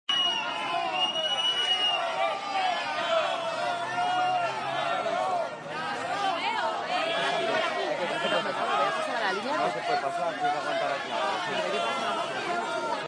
Miguel Blesa y Rodrigo Rato recibidos en la Audiencia Nacional con gritos y abucheos este viernes 3 de febrero de 2017